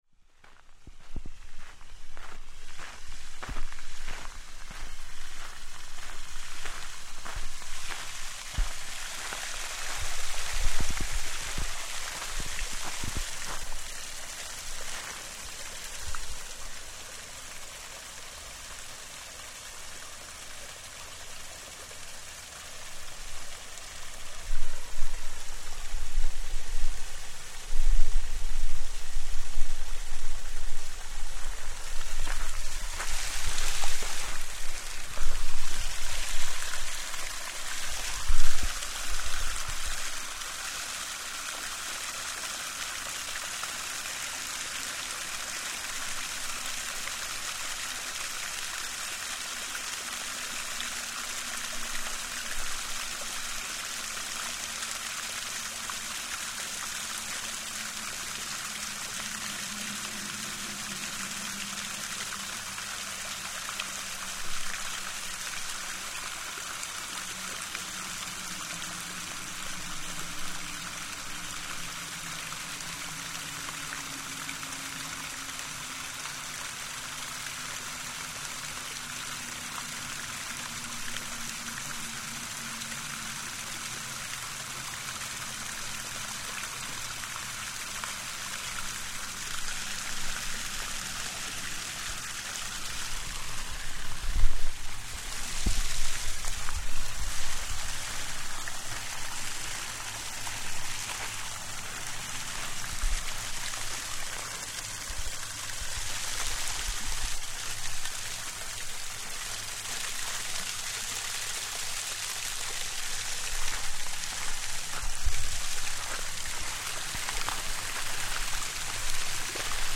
Three fountains
Three fountains and water features in the gardens behind Kadriorg Palace, Tallinn. The microphone moves from fountain to fountain from different angles and proximities, along with the crunch of the gravel paths underfoot.